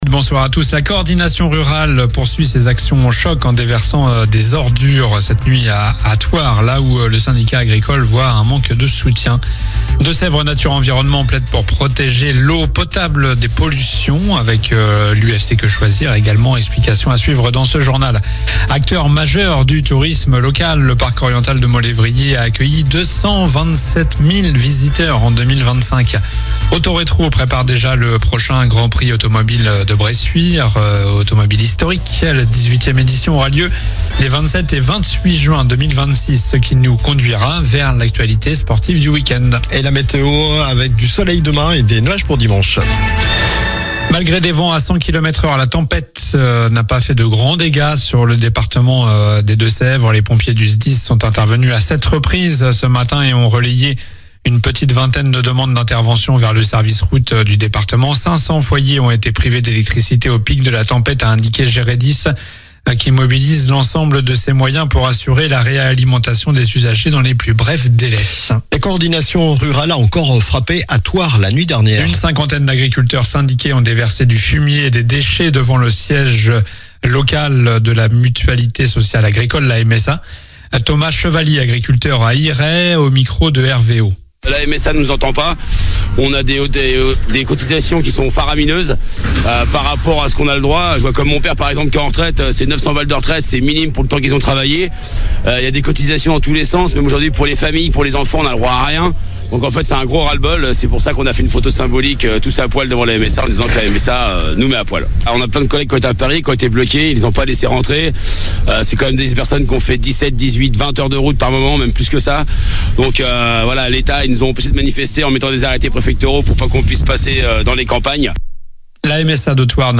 Journal du vendredi 09 janvier (soir)